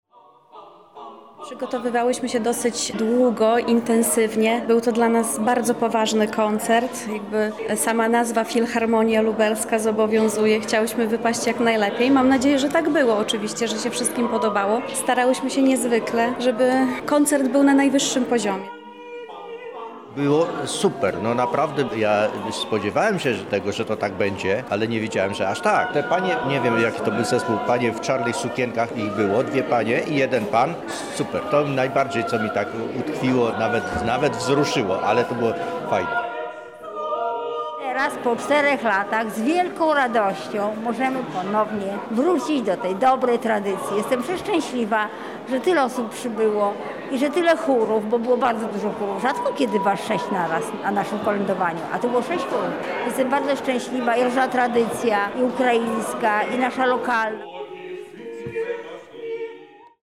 Pieśni wschodniosłowiańskie w Filharmonii Lubelskiej
W ubiegły czwartek wybrzmiały dźwięki dzwoneczków.
Gościliśmy chóry zarówno  z naszego miast jak i z Bielska Podlaskiego czy Chełma. Koncert cieszył się zainteresowaniem mieszkańców, a sala koncertowa była wypełniona po brzegi.
Na zakończenie zespoły oraz widzowie wspólnie odśpiewali kolędę.
kolędowanie.mp3